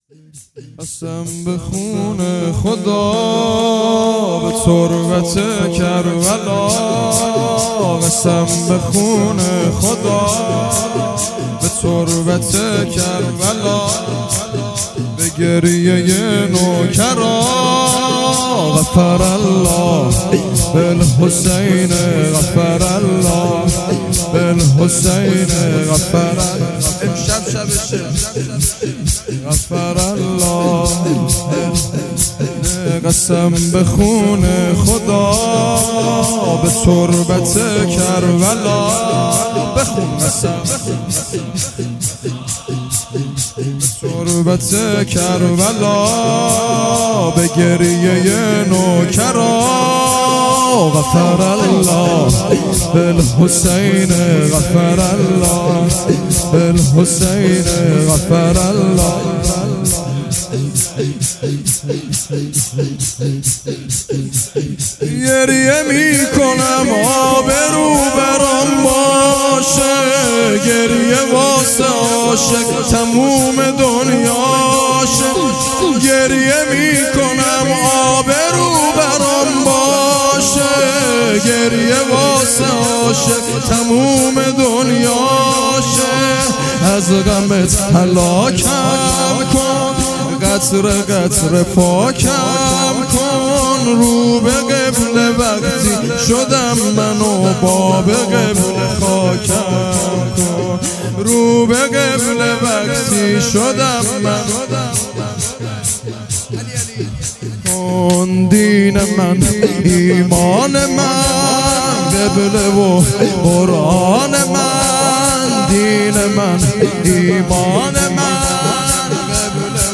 0 0 شور | قسم به خون خدا